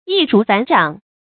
注音：ㄧˋ ㄖㄨˊ ㄈㄢˇ ㄓㄤˇ
易如反掌的讀法